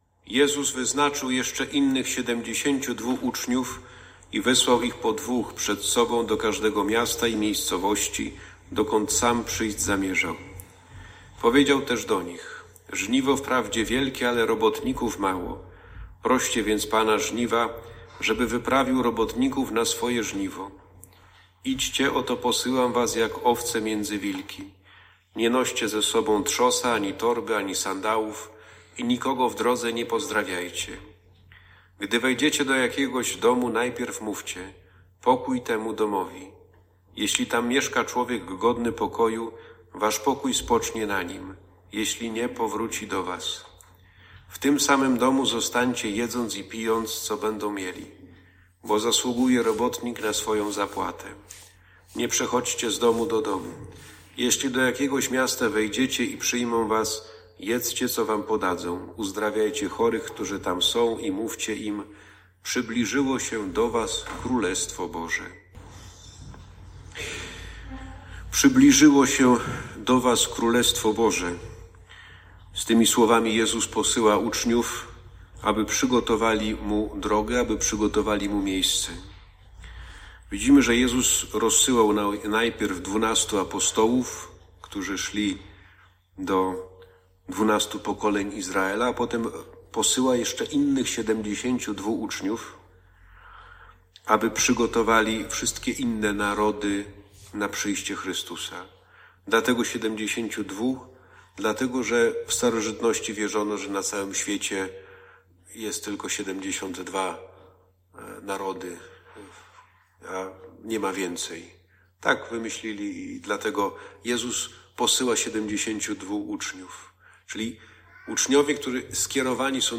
kazanie 3.07.2022